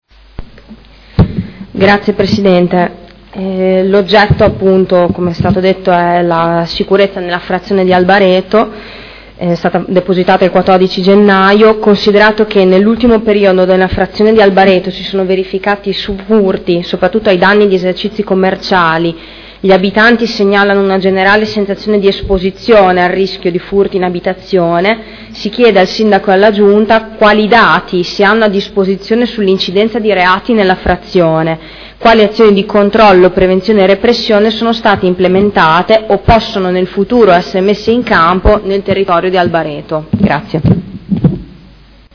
Seduta del 3 marzo. Interrogazione dei Consiglieri Morini e Stella (P.D.) avente per oggetto: Sicurezza ad Albareto